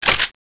reload.wav